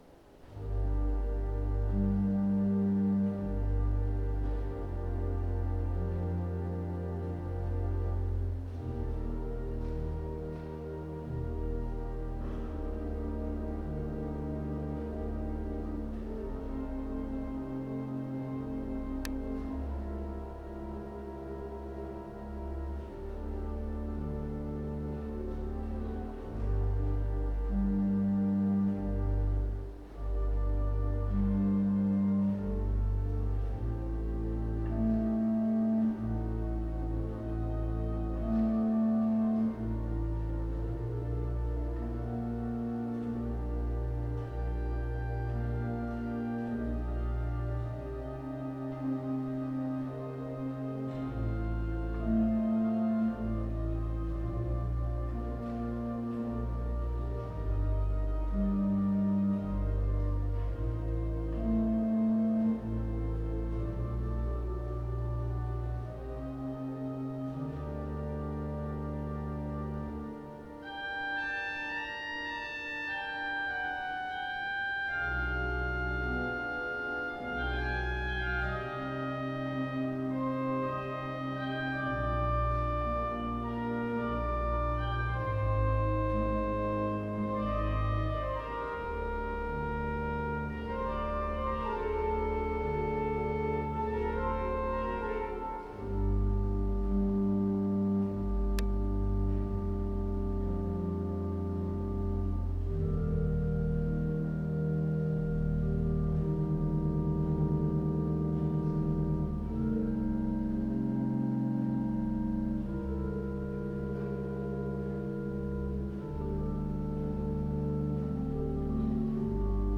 St Cyprian's Church Nottingham - Organ